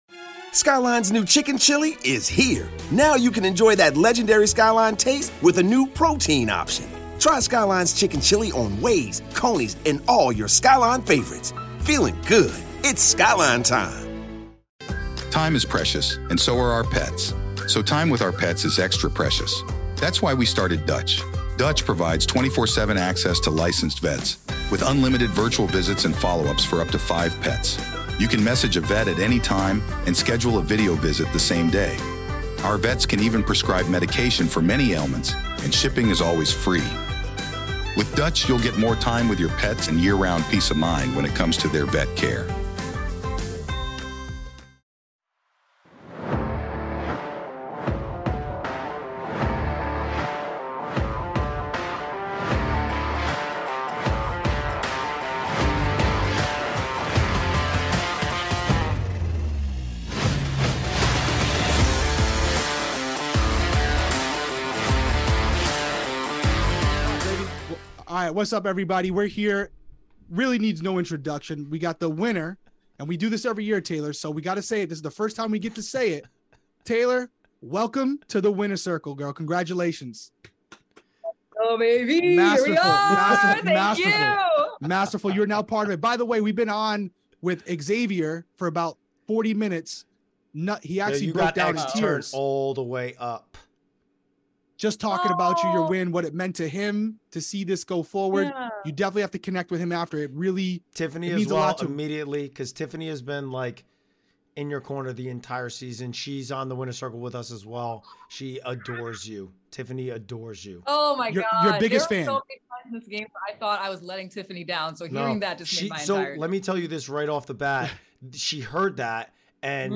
Derrick & Cody Interview Taylor Hale after her emotional victory on Big Brother 24. She not only won the show but also won AFP.